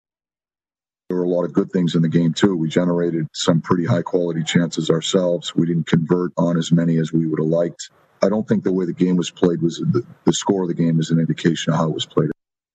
Sullivan said the Penguins didn’t actually play that badly.